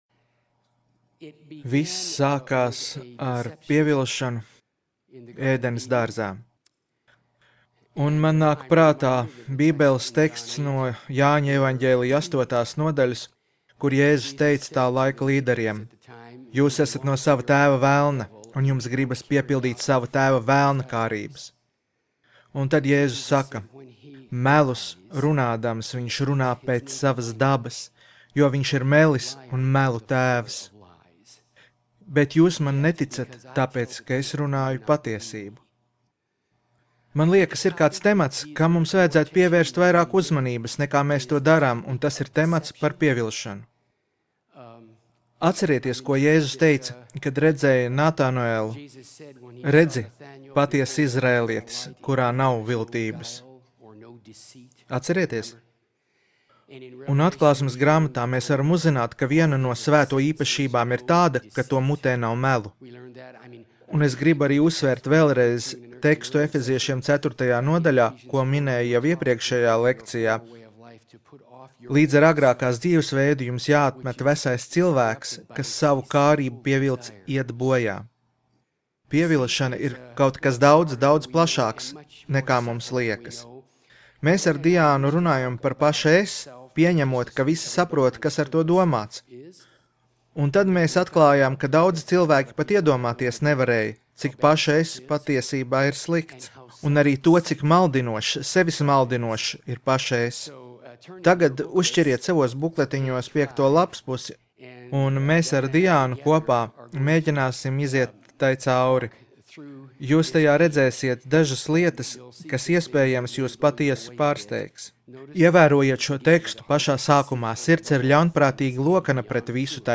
Skatīties video Seminārs - Kā pareizi nomirt... un dzīvot, lai par to stāstītu!